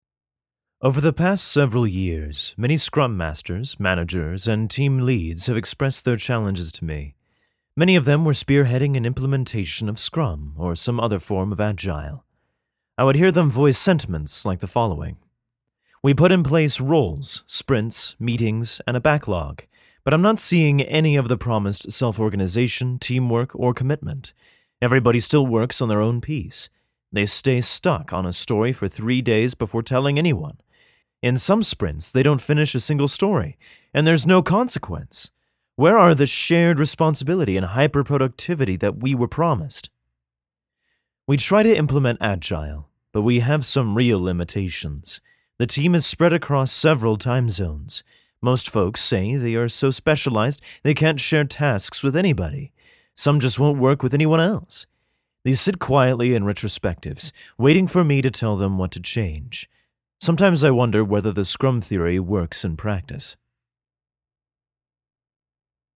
Classically Trained actor who specializes in Narration, commercial, video game, and animation voice over.
Sprechprobe: Industrie (Muttersprache):